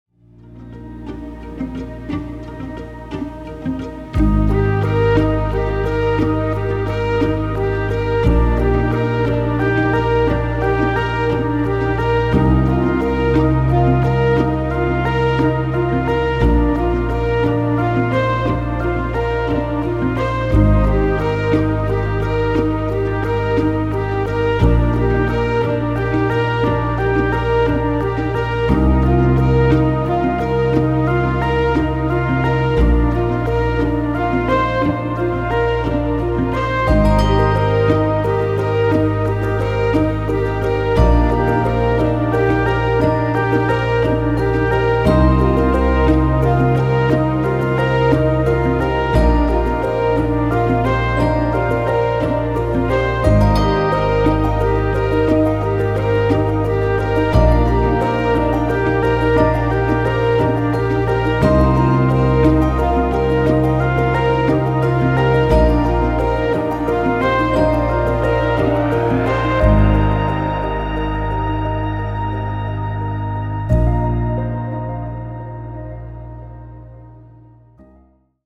Cozy Adventure